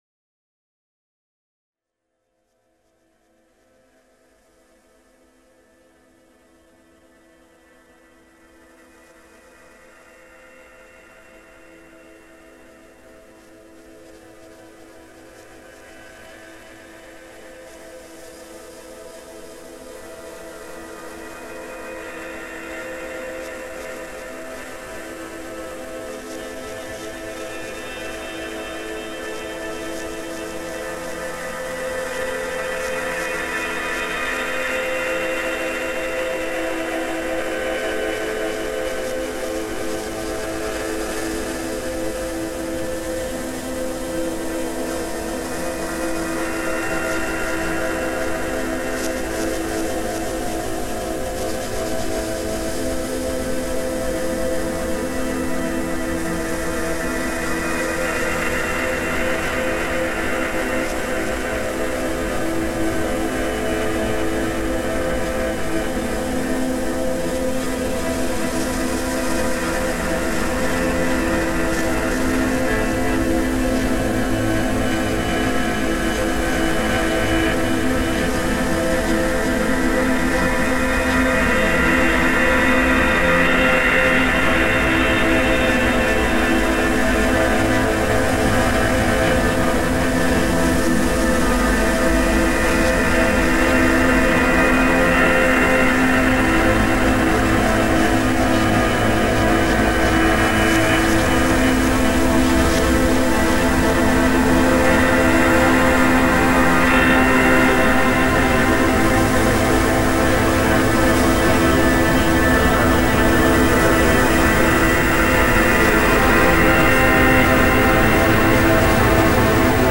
フランス発、ネオ・クラシカル＋アンビエントユニット、デビュー作。
Grand Piano, Organ, Guitar
Synth, Other [Field Recordings, Various Objects, Treatments]